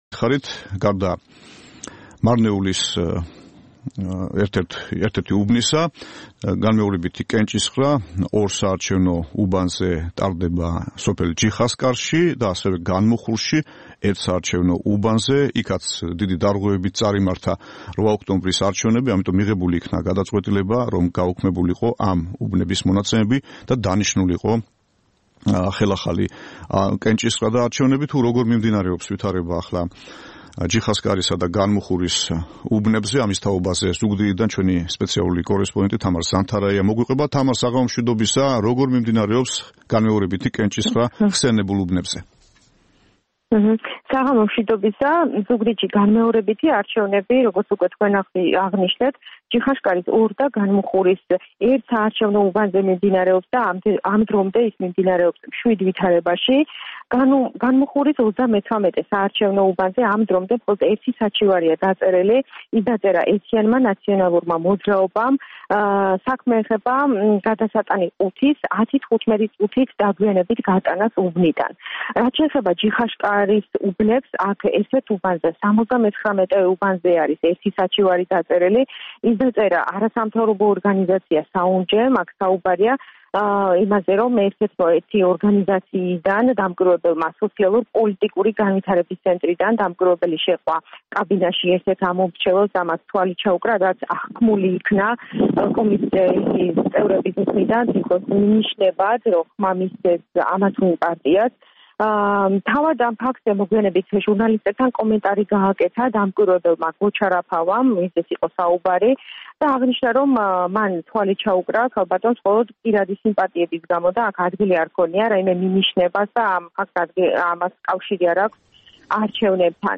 რეპორტაჟი ზუგდიდიდან